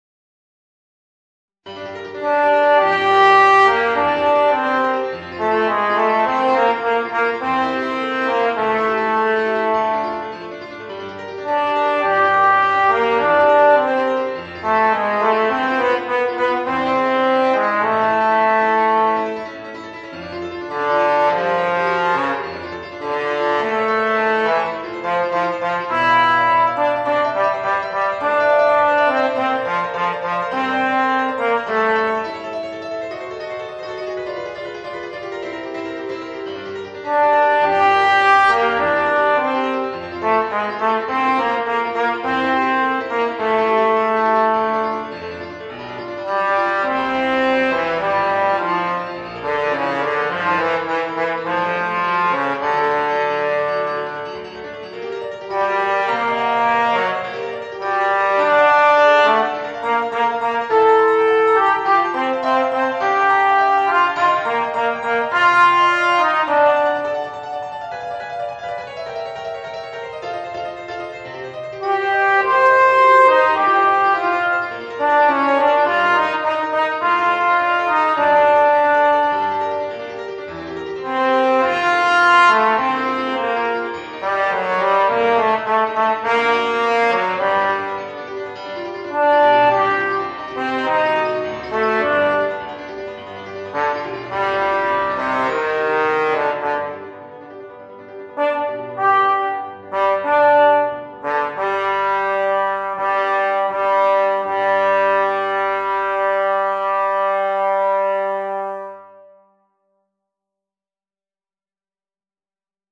Voicing: Alto Trombone and Piano